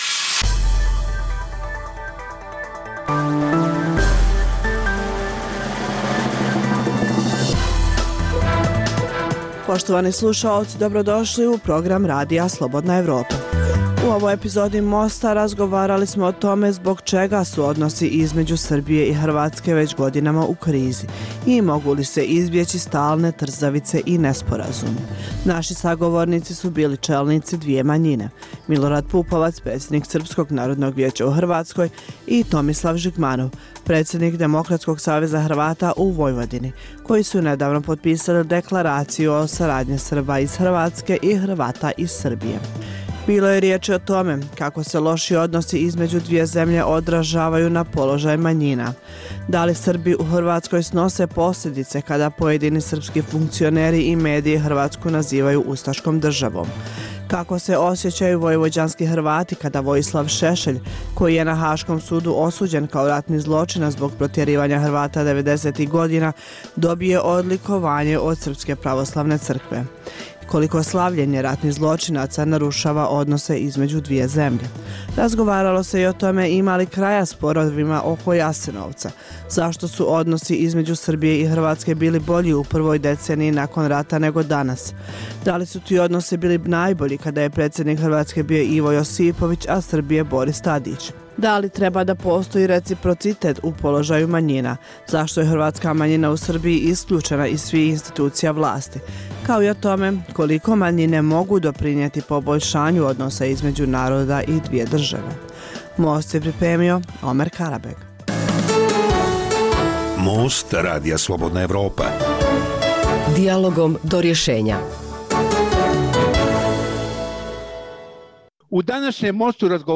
Dijaloška emisija o politici, ekonomiji i kulturi